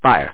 w2_fire.mp3